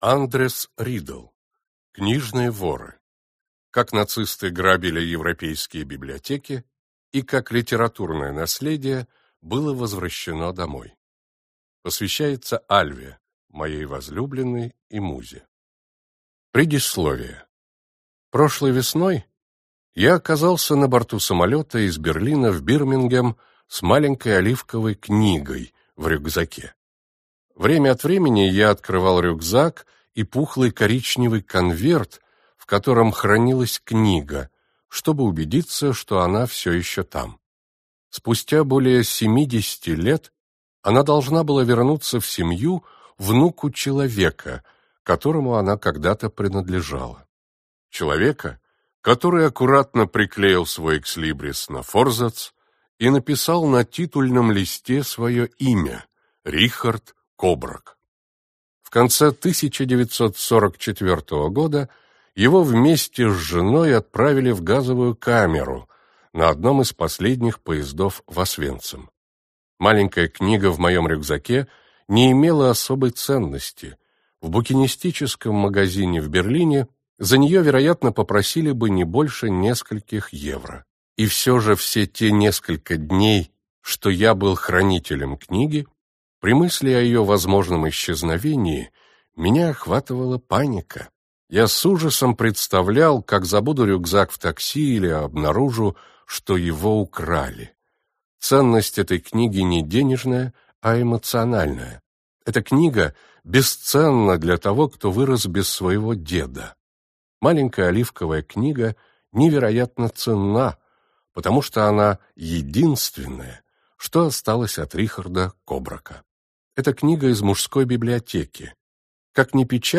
Аудиокнига Книжные воры | Библиотека аудиокниг